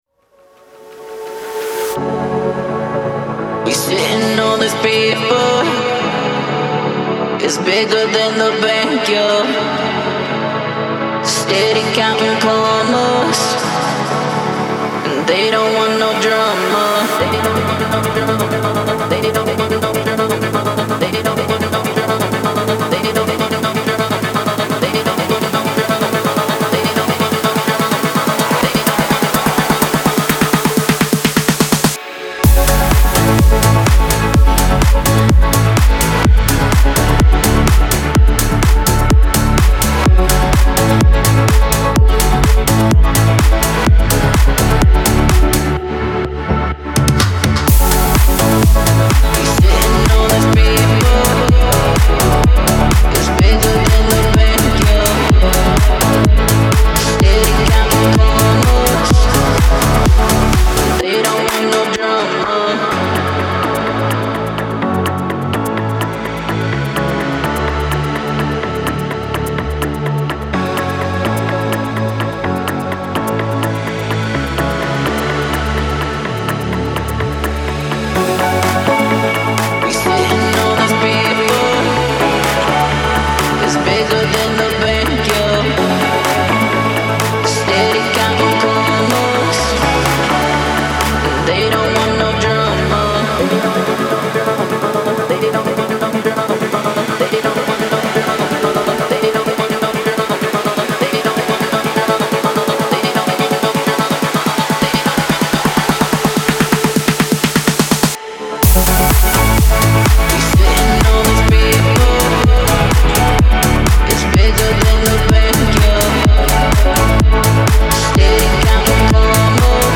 Genre : Électronique, Dance